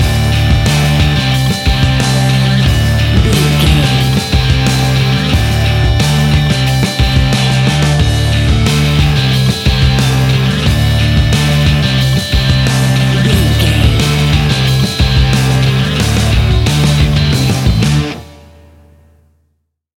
Ionian/Major
D
energetic
driving
aggressive
electric guitar
bass guitar
drums
hard rock
heavy metal
blues rock
rock instrumentals
heavy drums
distorted guitars
hammond organ